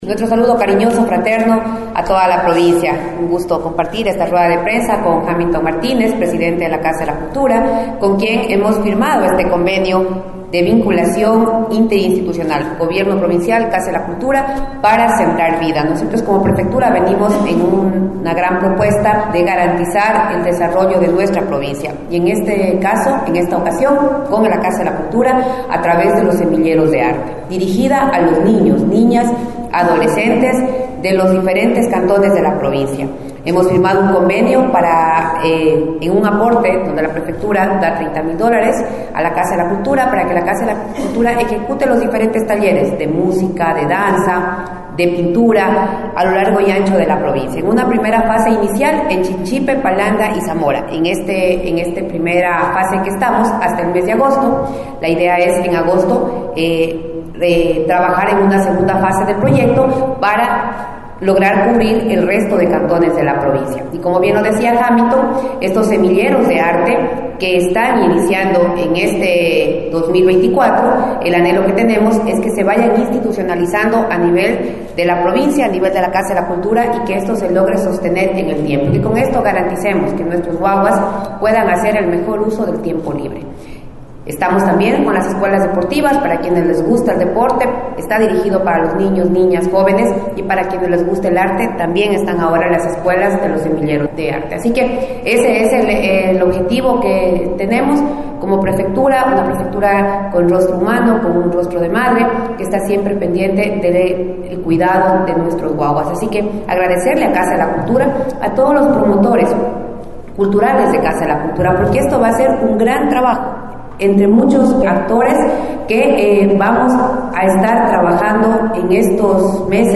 KARLA REÁTEGUI – PREFECTA